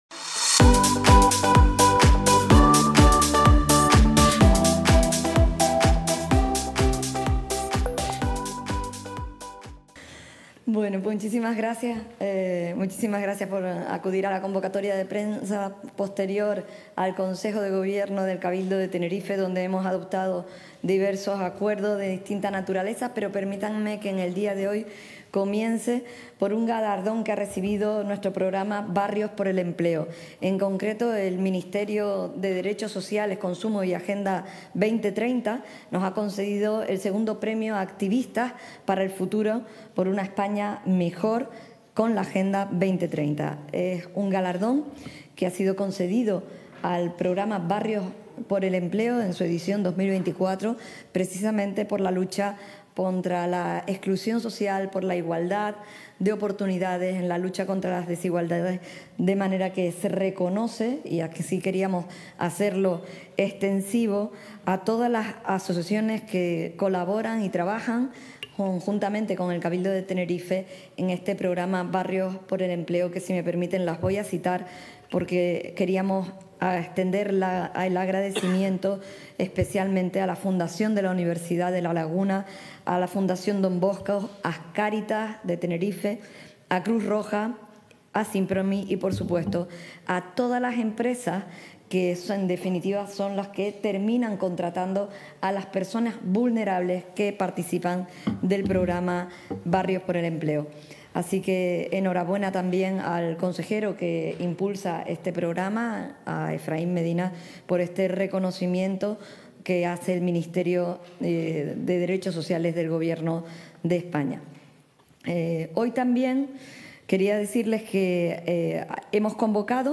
Recientemente emitido: Este miércoles, 11 de diciembre, a partir de las 11.30, emisión en directo de la rueda de prensa de presentación de los acuerdos del Consejo de Gobierno.